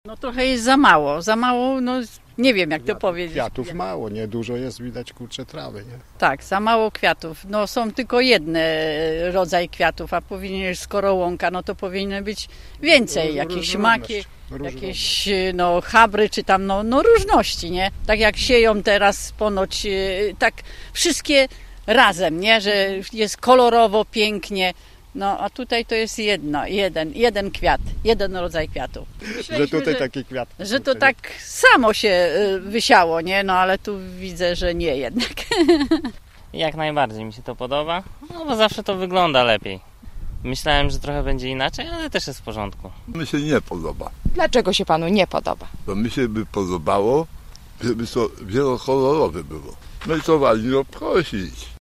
Podczas spaceru w Parku 750 lecia zapytaliśmy mieszkańców jak oceniają efekty pracy urzędników.
– Brakuje kolorów, a całość przesłania wysoka trawa – mówią nam mieszkańcy: